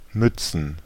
Ääntäminen
Ääntäminen Tuntematon aksentti: IPA: /ˈmʏ.t͡sn̩/ Haettu sana löytyi näillä lähdekielillä: saksa Käännöksiä ei löytynyt valitulle kohdekielelle. Mützen on sanan Mütze monikko.